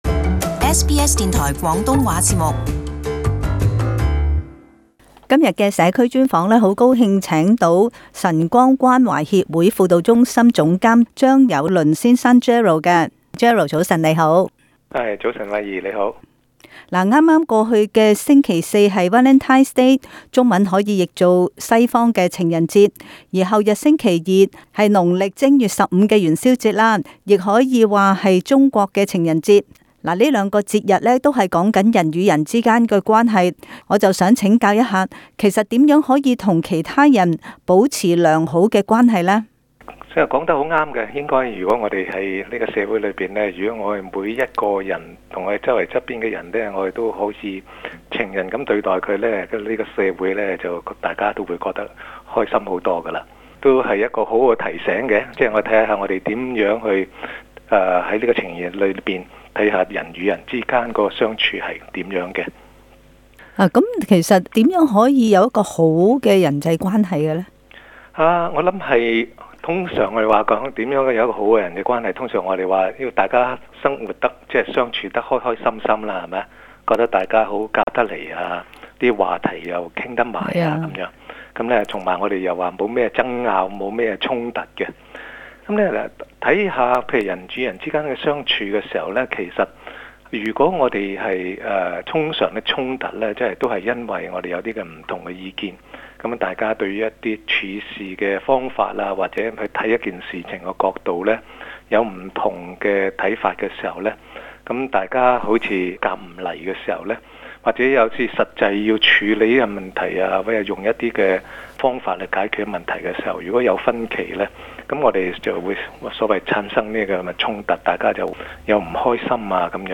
【社區專訪】如何改善人際關係